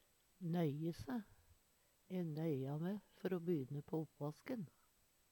nøye se - Numedalsmål (en-US)